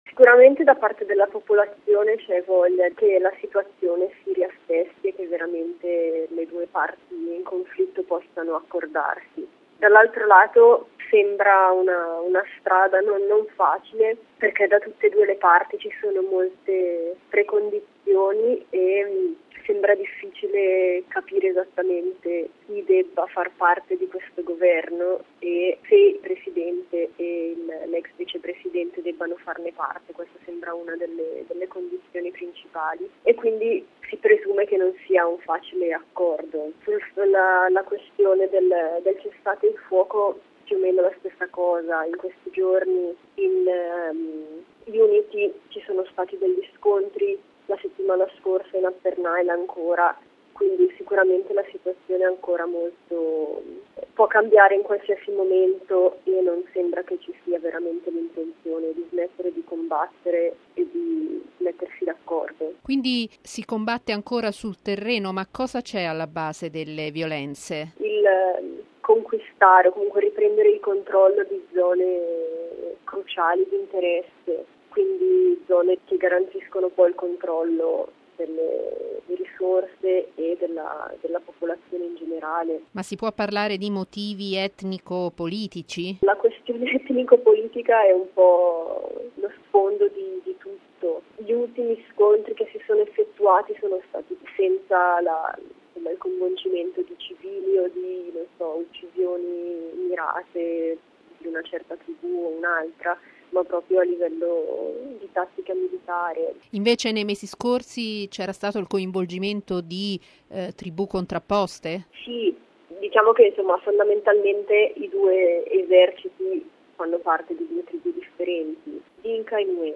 raggiunta telefonicamente a Juba